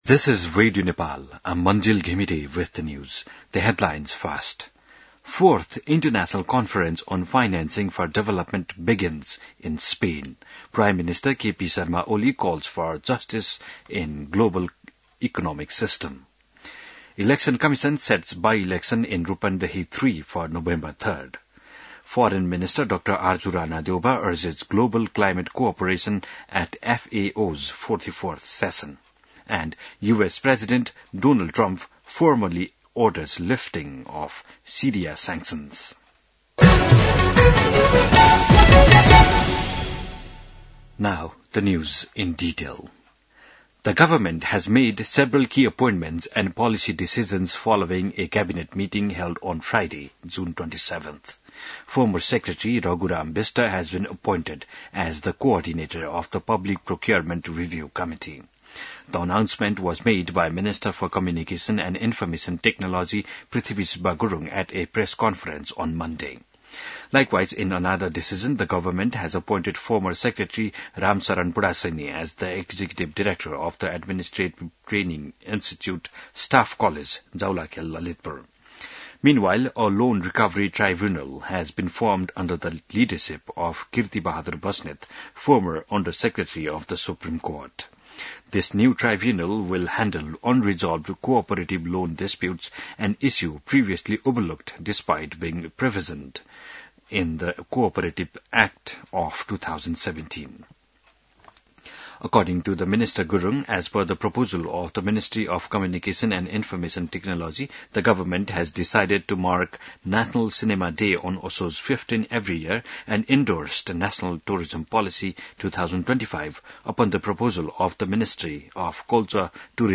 An online outlet of Nepal's national radio broadcaster
बिहान ८ बजेको अङ्ग्रेजी समाचार : १७ असार , २०८२